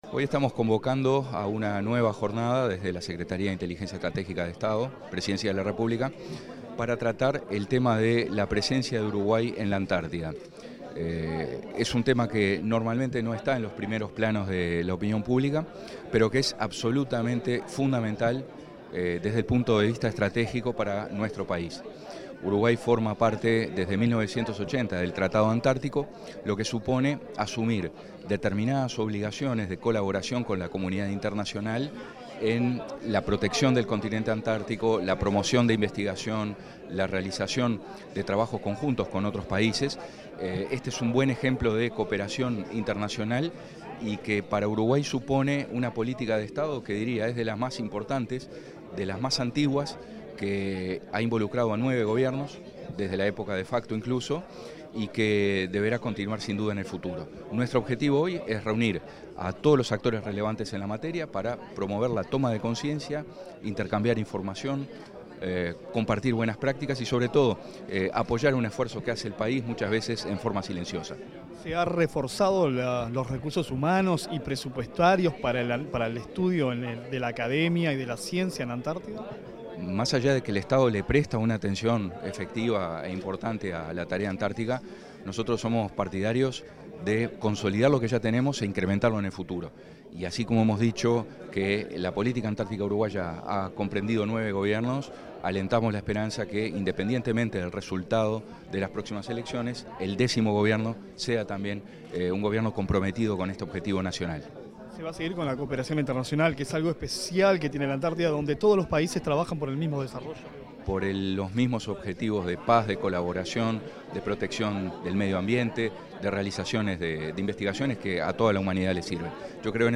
Entrevista al director de la Secretaria de Inteligencia Estratégica de Estado, Álvaro Garcé
El director de la Secretaria de Inteligencia Estratégica de Estado, Álvaro Garcé, dialogó con Comunicación Presidencial en la Torre Ejecutiva, antes